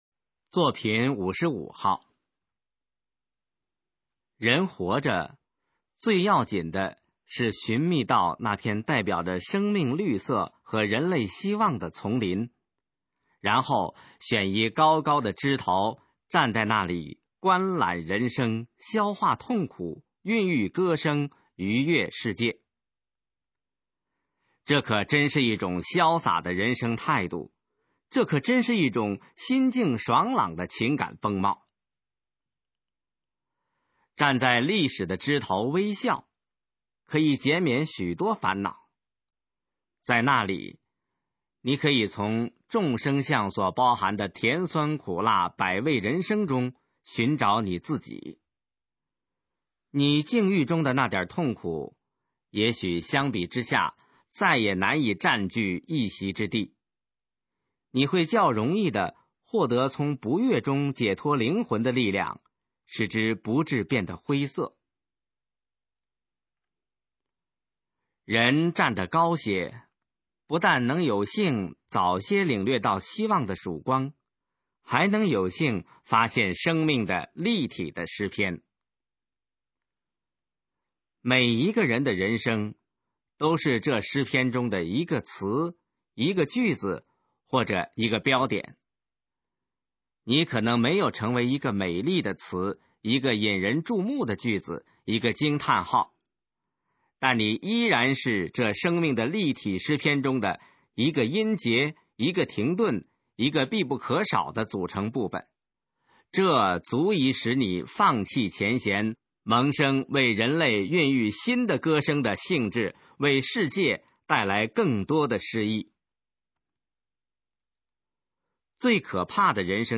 首页 视听 学说普通话 作品朗读（新大纲）
《站在历史的枝头微笑》示范朗读